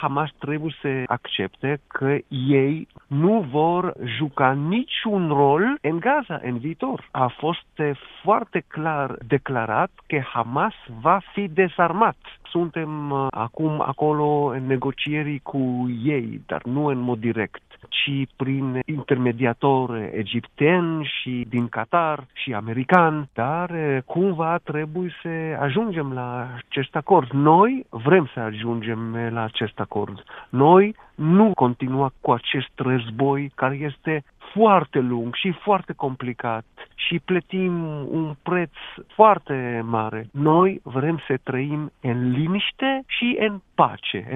Într-un interviu pentru Radio România Actualităţi, diplomatul a precizat că ţara sa nu doreşte să continue operaţiunile militare şi pledează pentru un acord şi pentru pace în zonă: